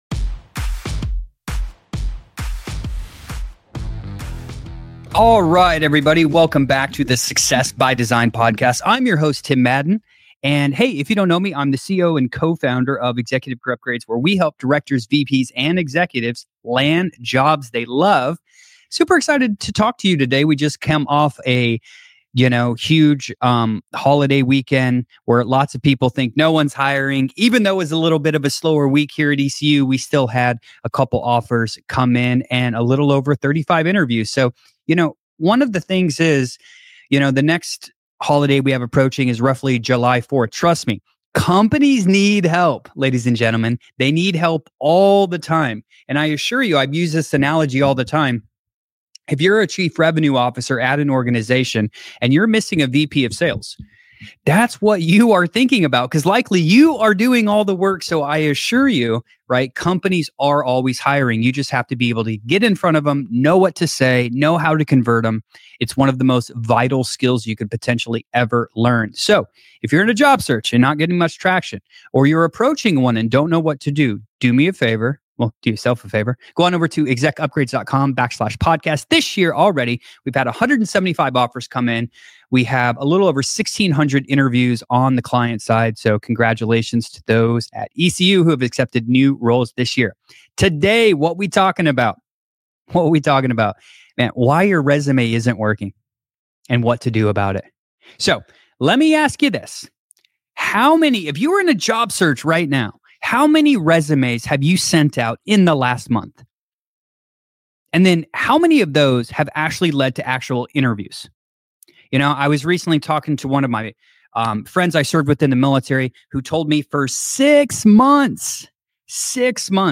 Missed the live discussion?